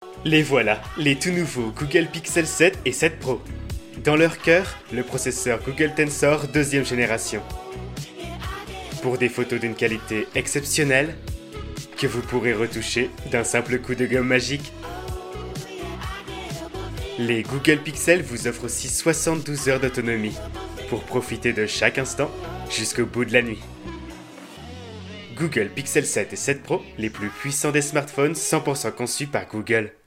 Bandes-son
Voix off